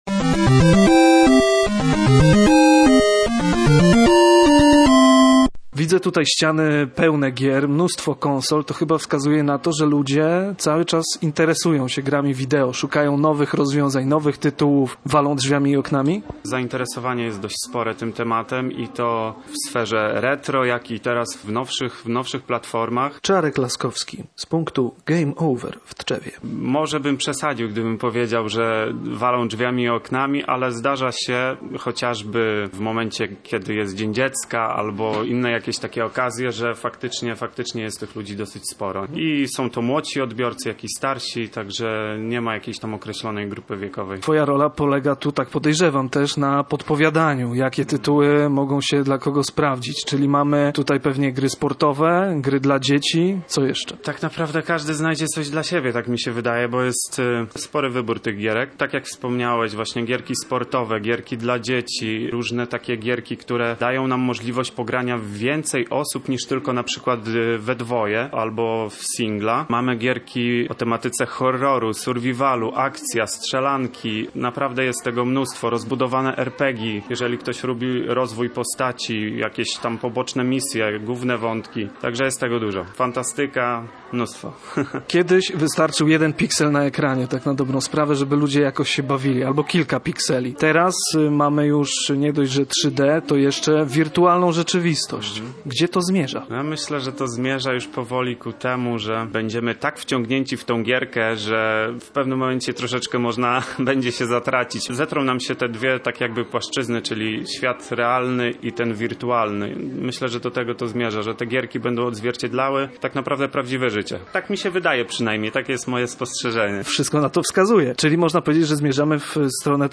Przygoda zaczyna się w punkcie o wymownej nazwie „Game Over” w Tczewie